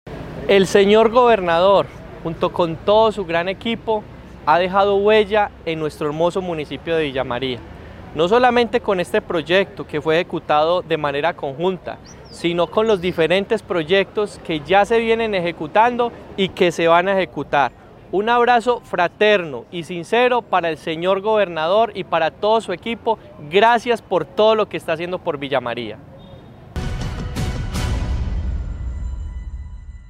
Alcalde de Villamaría, Jonier Alejandro Ramírez.